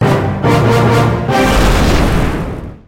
Dark Arena Jingle